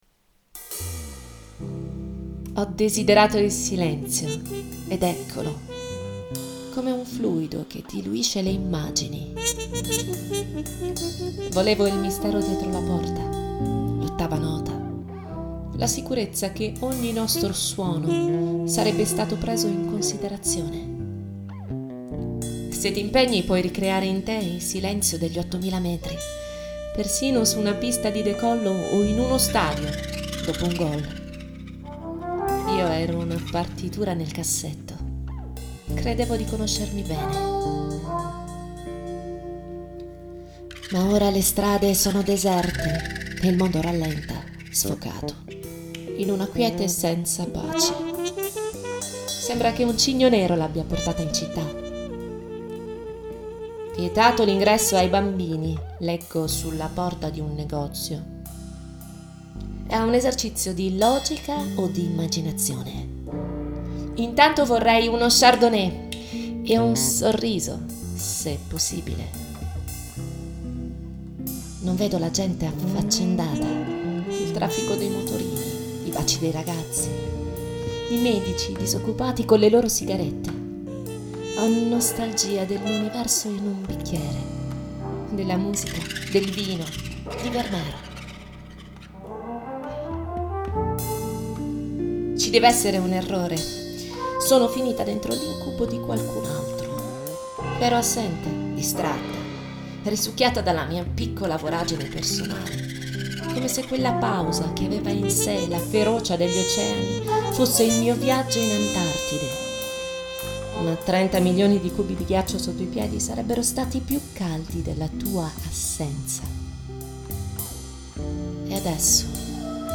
Audio libri